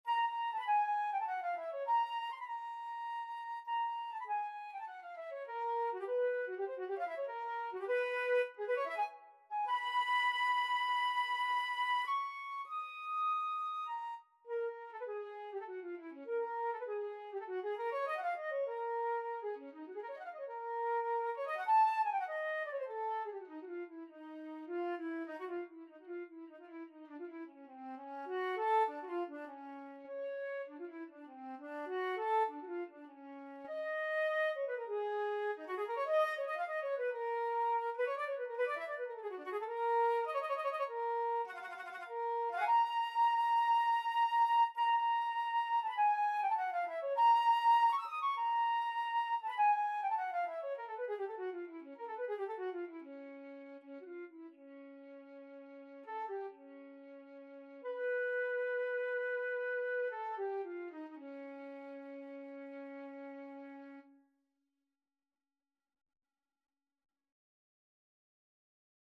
Flute (LARGE)
Trs modr
3/4 (View more 3/4 Music)
C5-E7
Instrument:
Classical (View more Classical Flute Music)